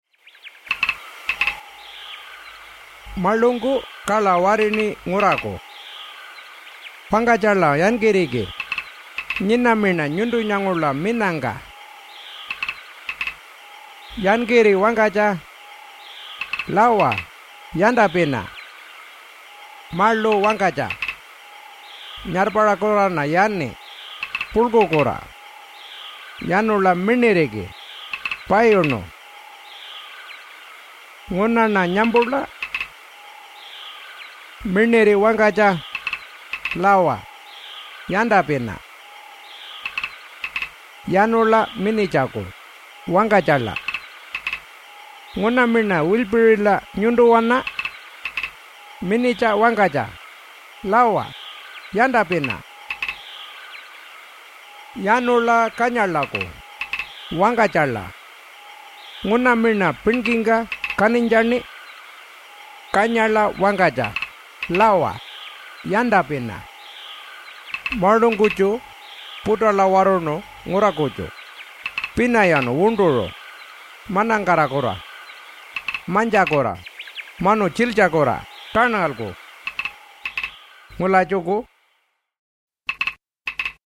Jukurrpa Stories